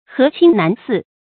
河清難俟 注音： ㄏㄜˊ ㄑㄧㄥ ㄣㄢˊ ㄙㄧˋ 讀音讀法： 意思解釋： 俟：等待。